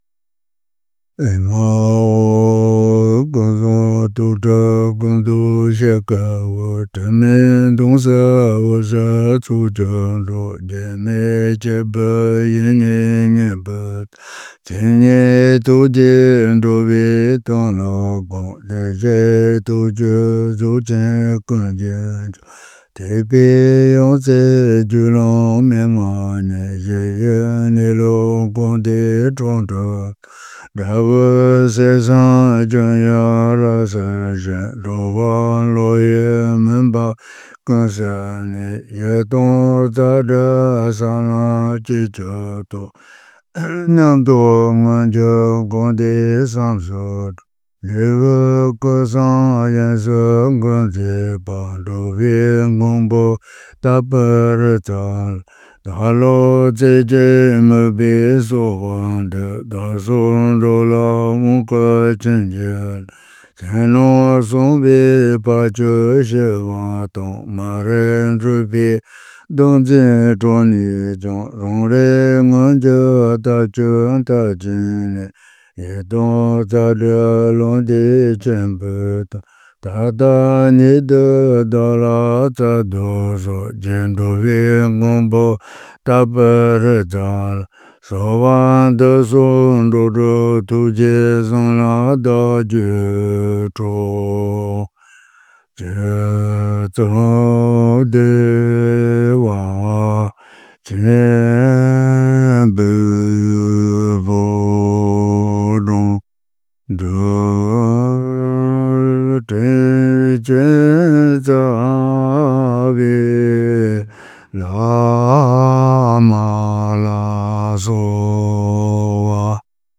YLTNR_TapihritsaInvocationGuruYoga.mp3